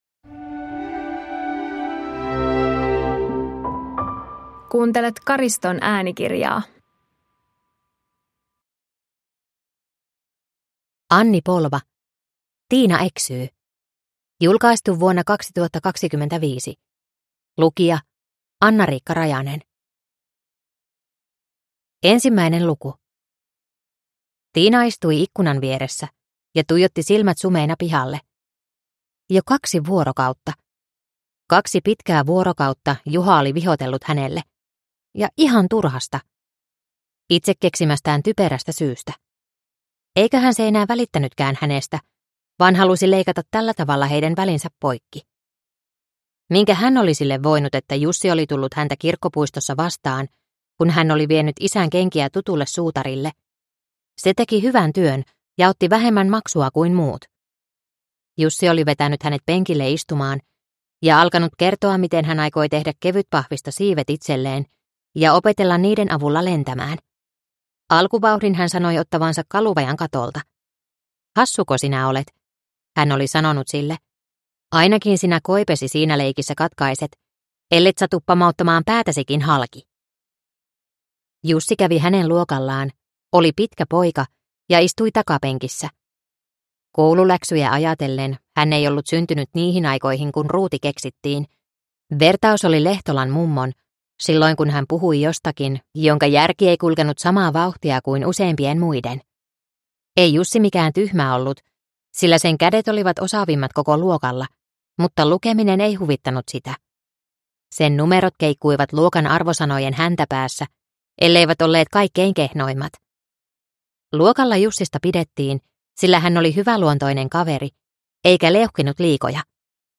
Tiina eksyy (ljudbok) av Anni Polva | Bokon